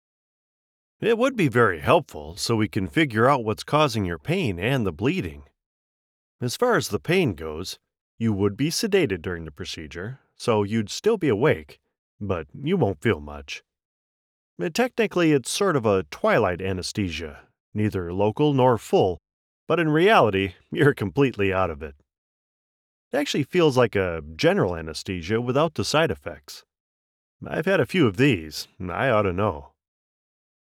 Role of "Doctor" I performed recently for an eLearning video
North American (General); North American (Midwest)
Middle Aged